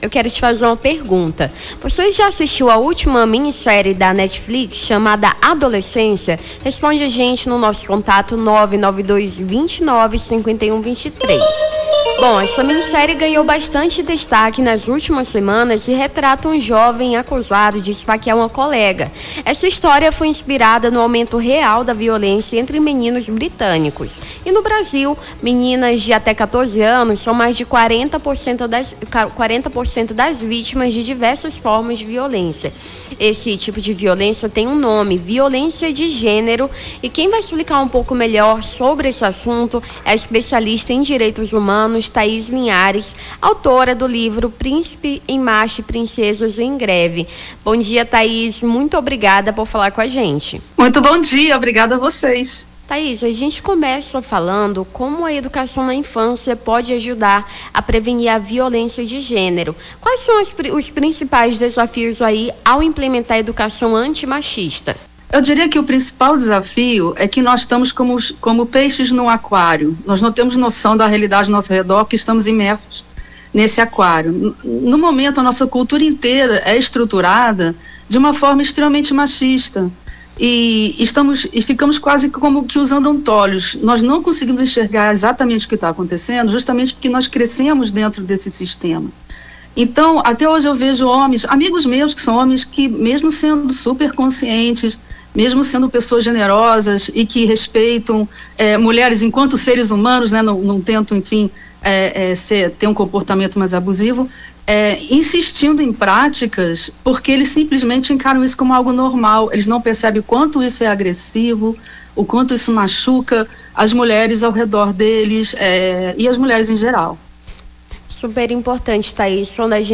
Nome do Artista - CENSURA - ENTREVISTA VIOLÊNCIA DE GÊNERO (24-03-25).mp3